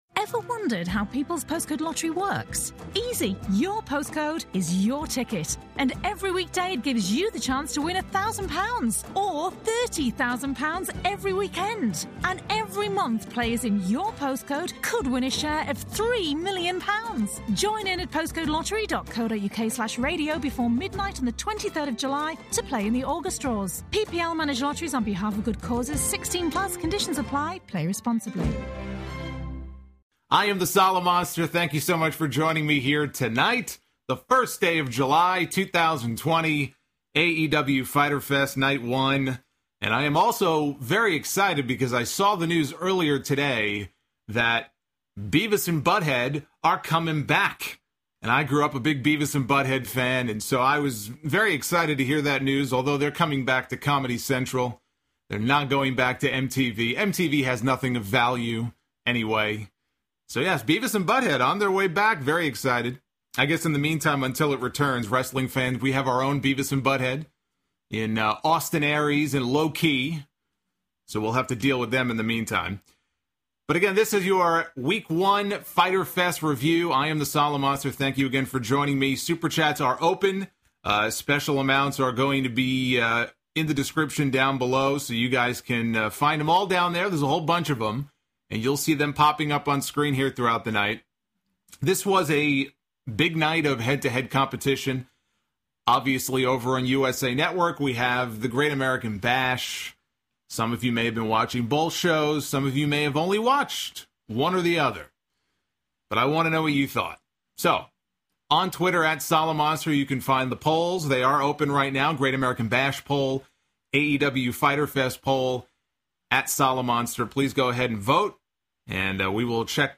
Plus, we've got an impromptu UK crate unboxing, your phone calls and more.